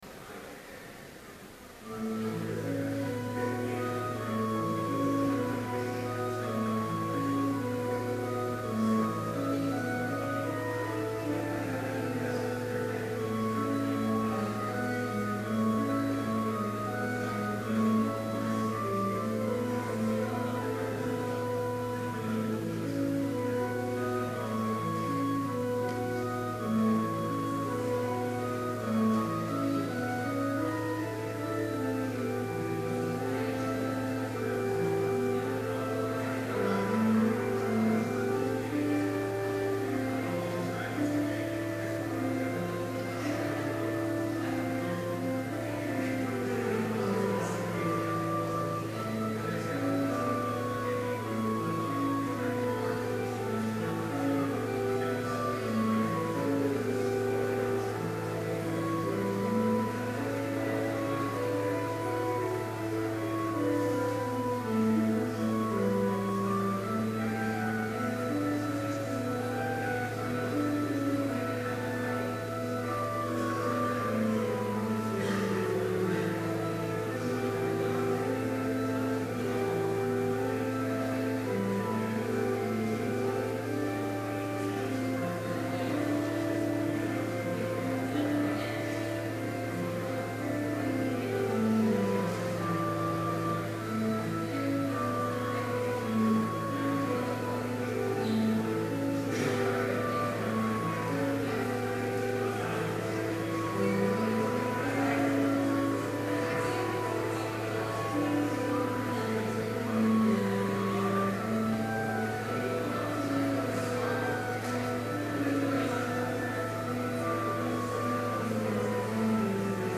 Complete service audio for Chapel - April 23, 2012